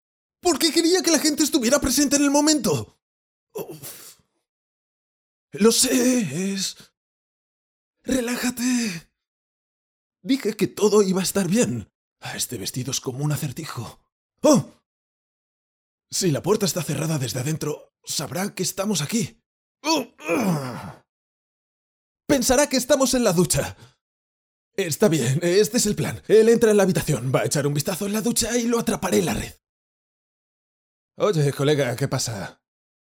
doblaje de película